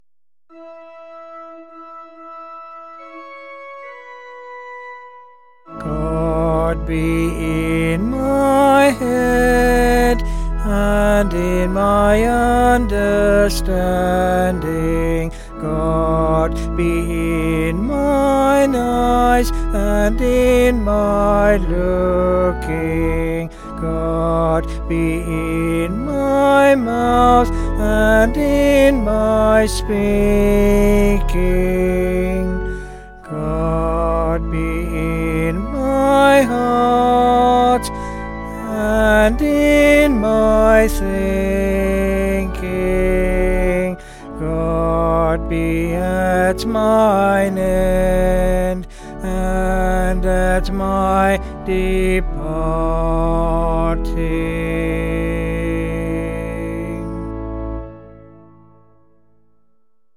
Vocals and Organ   702.2kb Sung Lyrics